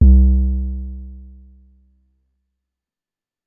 808 [ Bounce ].wav